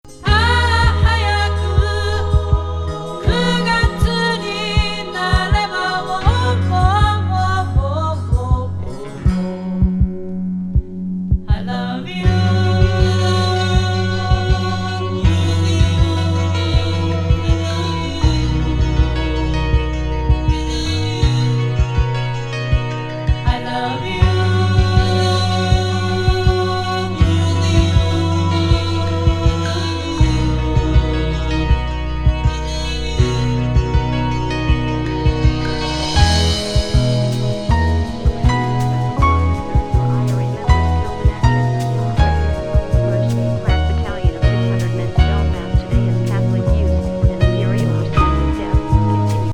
極上バレア・メロウ!